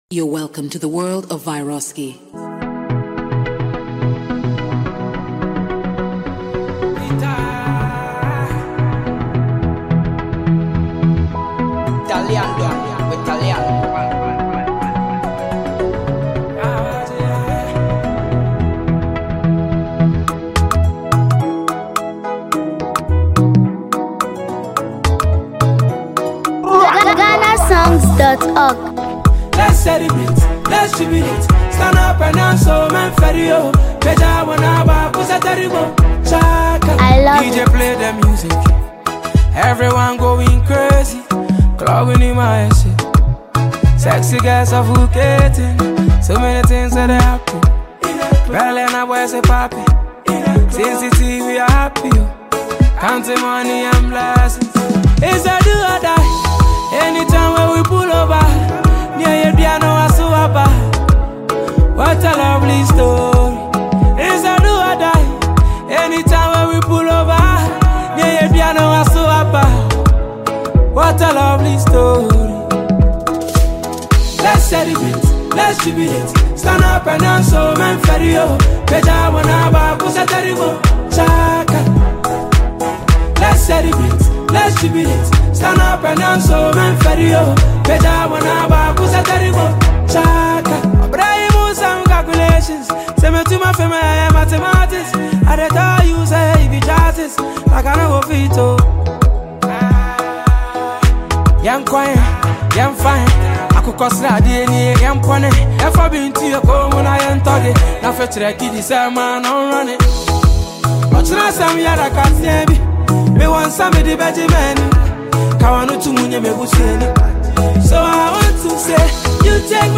emotional vocals and deep storytelling